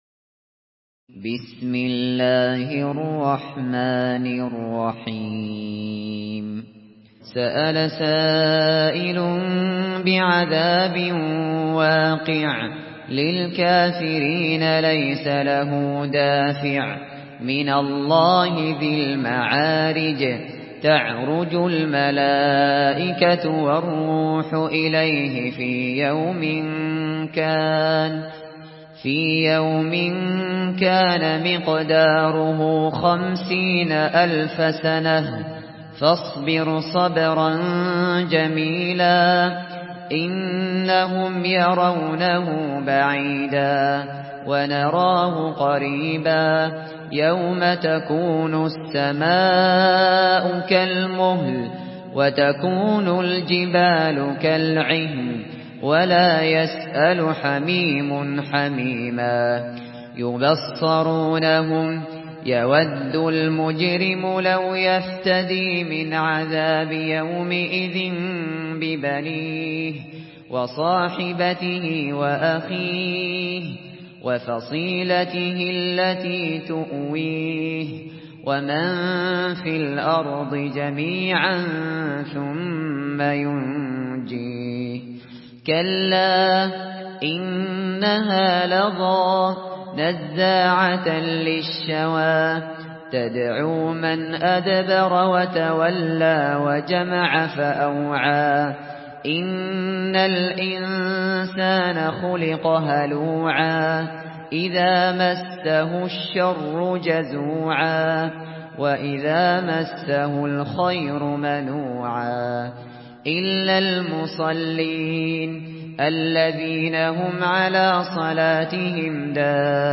Surah আল-মা‘আরেজ MP3 by Abu Bakr Al Shatri in Hafs An Asim narration.
Murattal Hafs An Asim